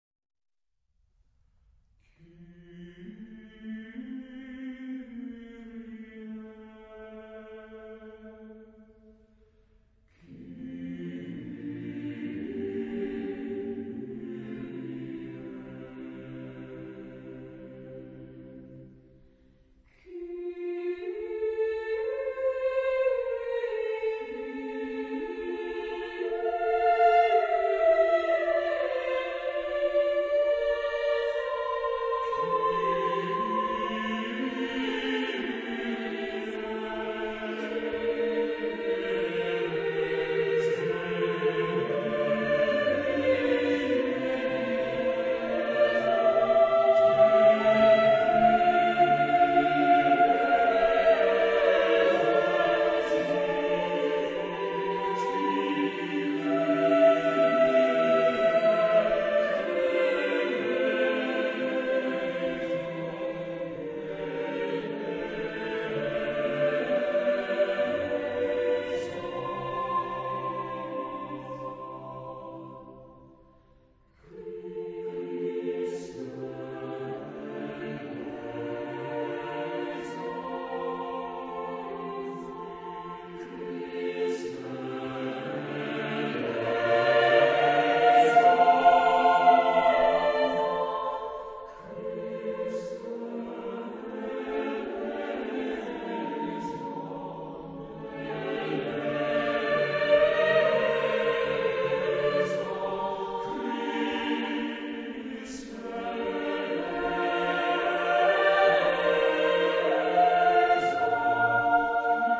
SATB (4 voces Coro mixto) ; Partitura general.
Sagrado. Romántico.